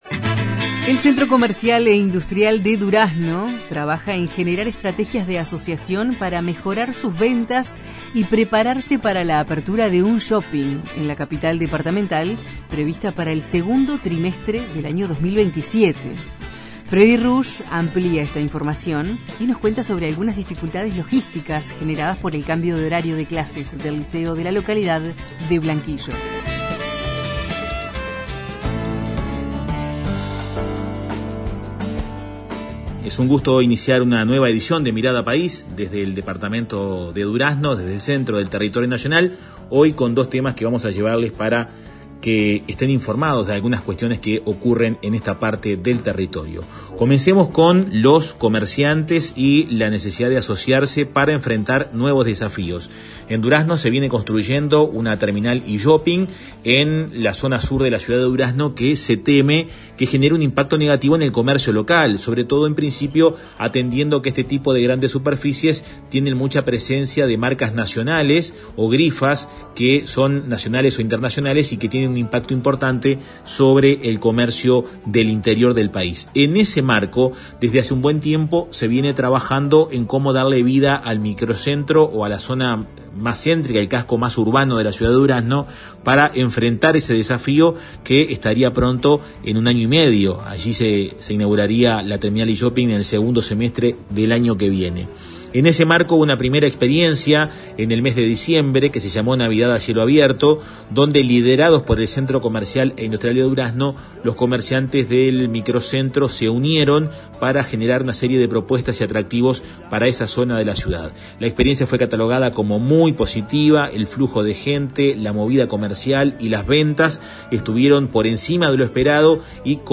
Los informes de nuestros corresponsales en Colonia (Oeste), Durazno y Lavalleja.